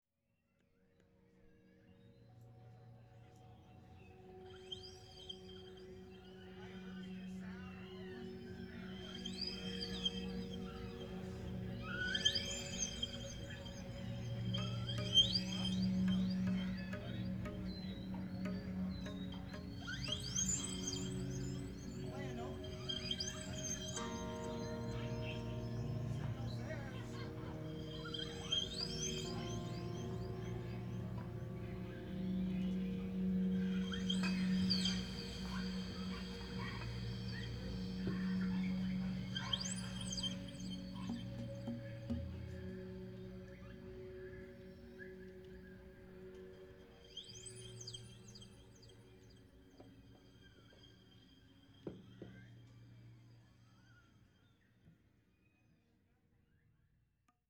Genre: Dub, Downtempo, Tribal.